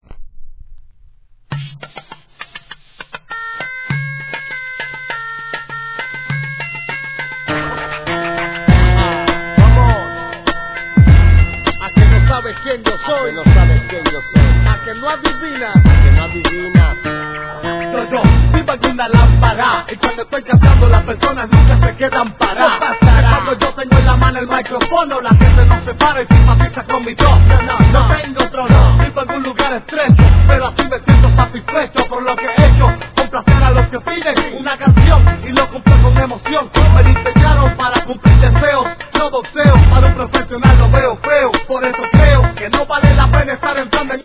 1. HIP HOP/R&B
■REGGAETON